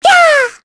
Mirianne-Vox_Casting1_kr.wav